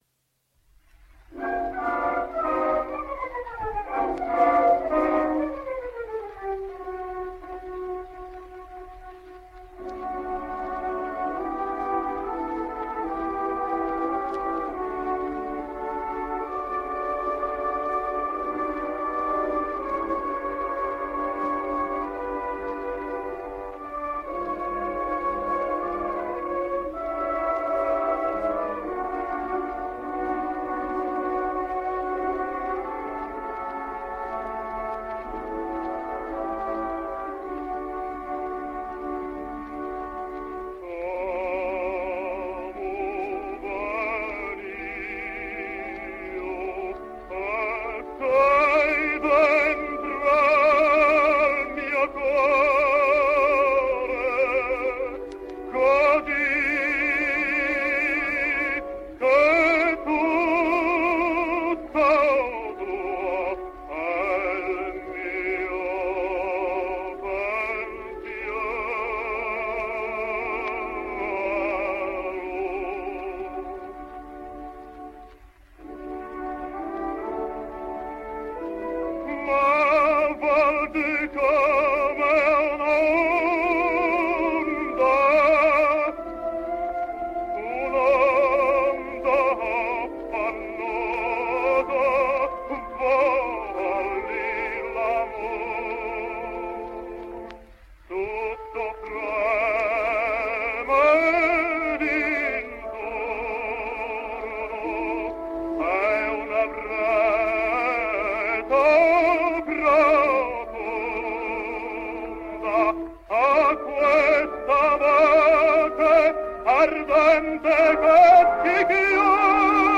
Here he is as a baritone singing an Aria from Catalano’s La Wally